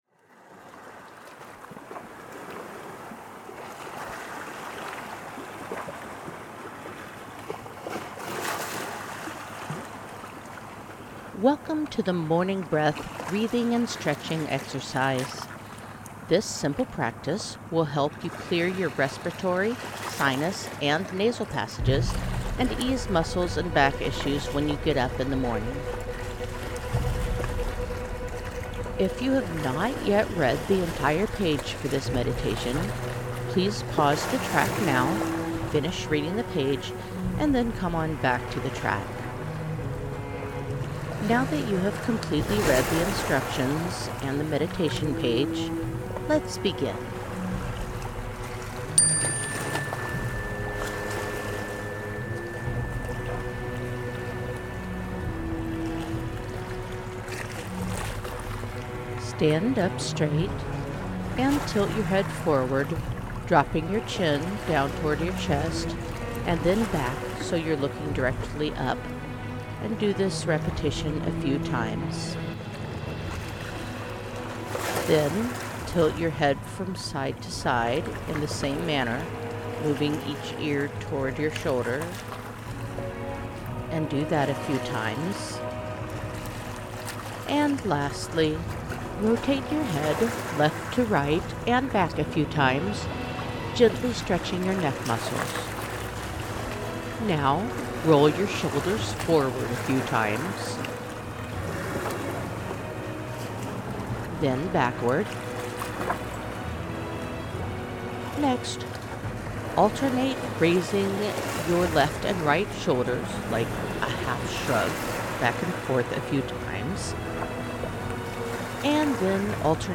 guided meditation track uses binaural beats, in addition to isochronic tones to help stimulate the brain and send it "wake up!" messages, the use of stereo headphones is very highly recommended.
The guided meditation track uses a chime to begin this meditation, as do most of the meditations in this series.